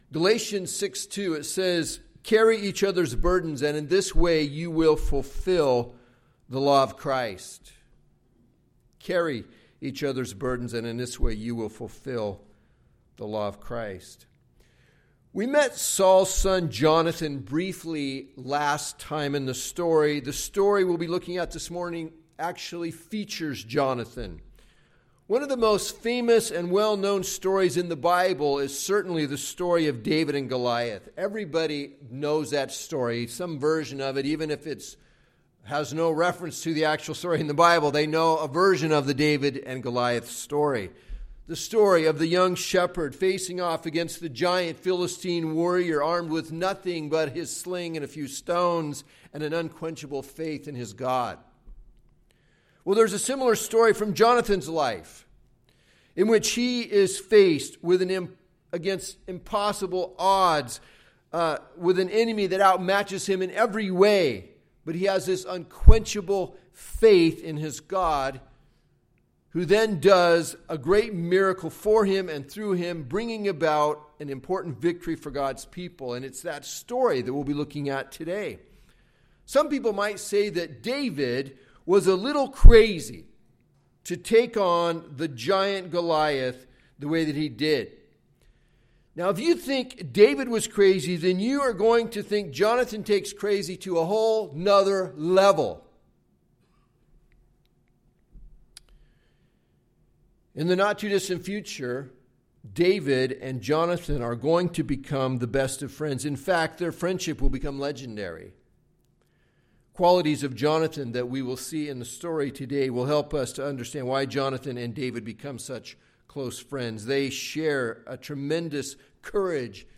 Recent Sundays